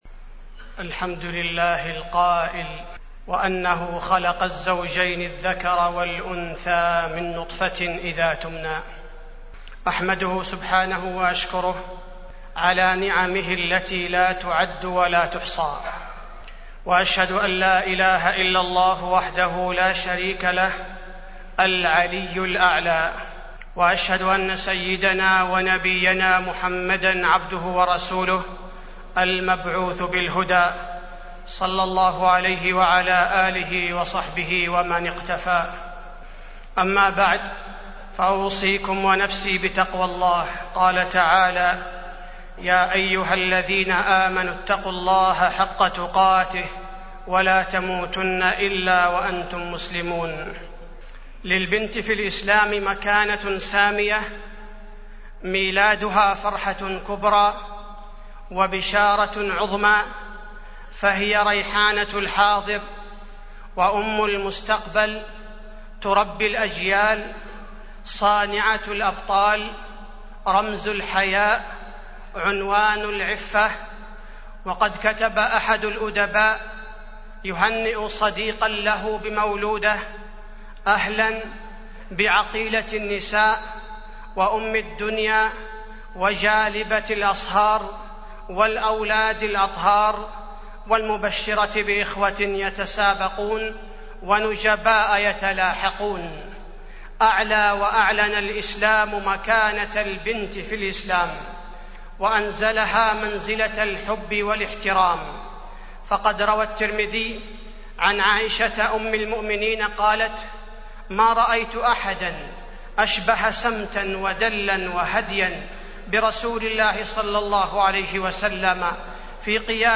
تاريخ النشر ١٠ ربيع الثاني ١٤٢٨ هـ المكان: المسجد النبوي الشيخ: فضيلة الشيخ عبدالباري الثبيتي فضيلة الشيخ عبدالباري الثبيتي تربية البنات على الاستقامة